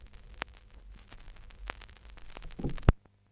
B面の音楽が終わってレコード針が上がったときのノイズ音はこちらです。
B面が終わってレコード針が上がったときのノイズ音